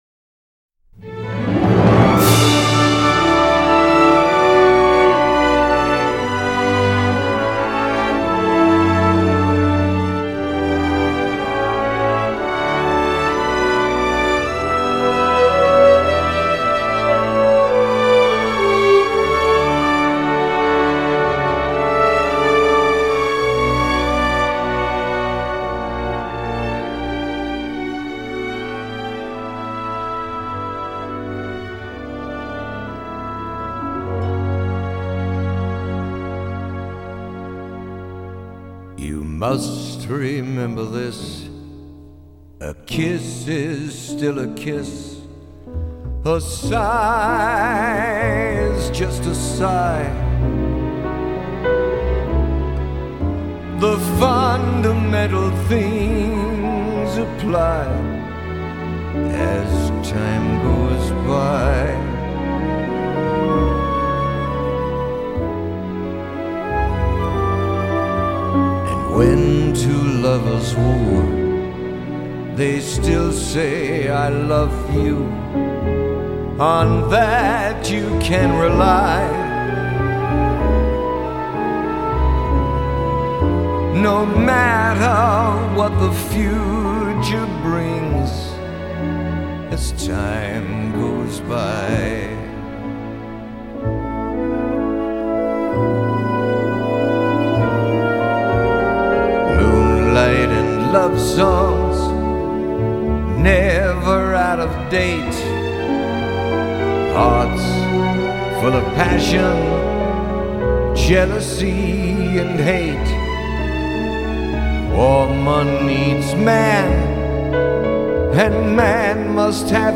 现场演唱原音收录制作完成，气势磅礡